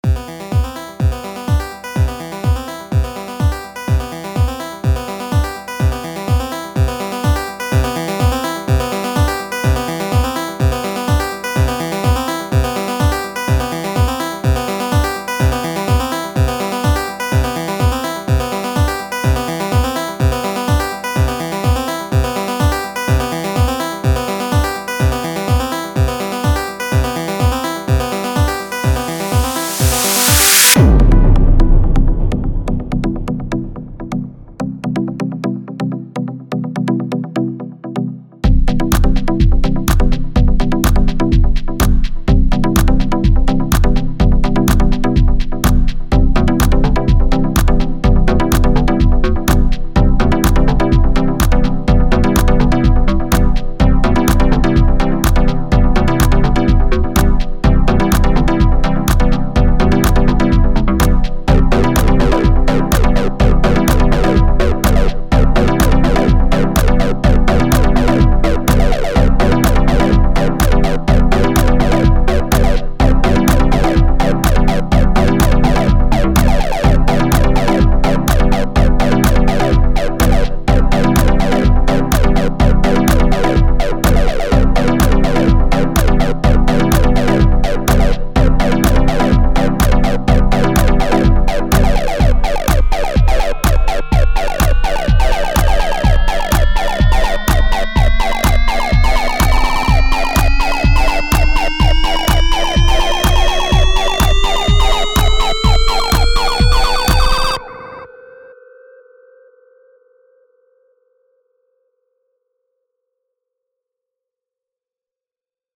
2) "mind kontrol ultra" - A sucker for simplistic chiptune, I am...